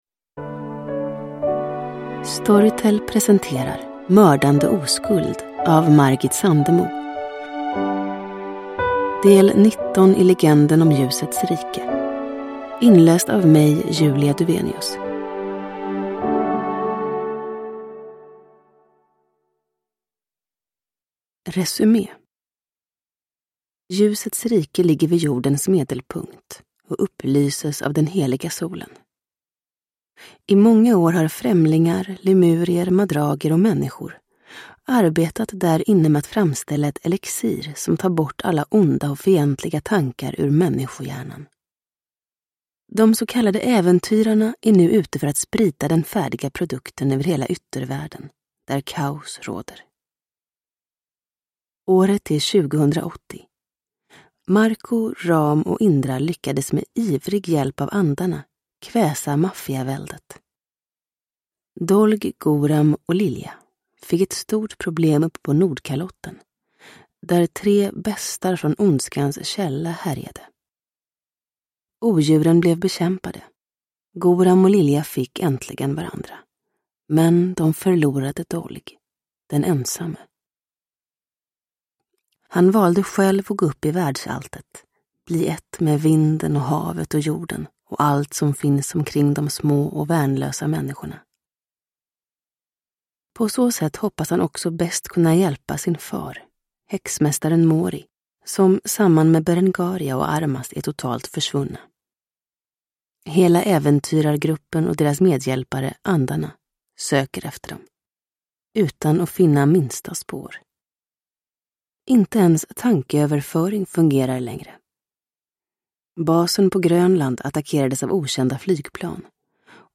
Uppläsare: Julia Dufvenius